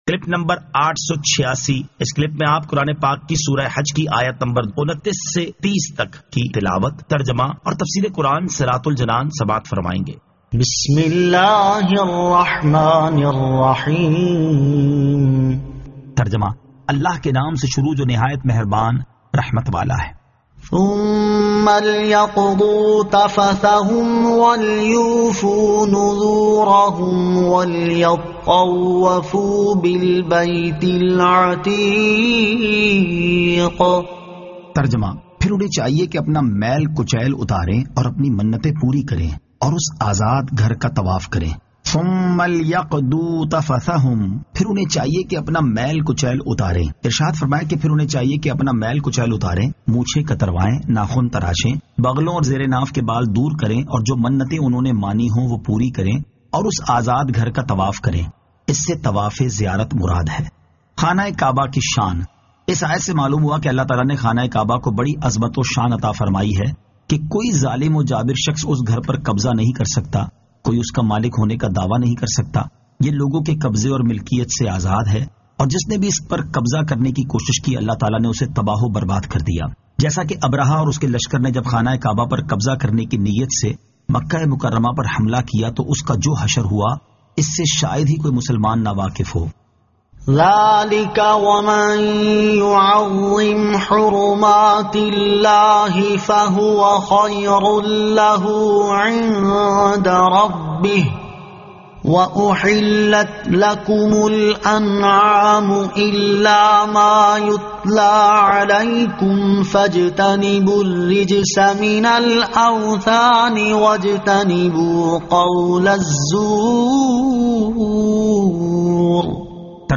Surah Al-Hajj 29 To 30 Tilawat , Tarjama , Tafseer